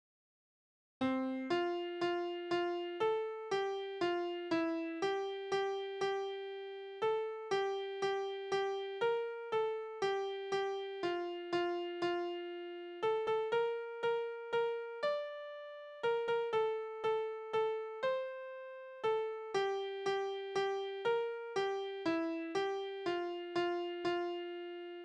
Tonart: F-Dur Taktart: 3/4 Tonumfang: große None Besetzung: vokal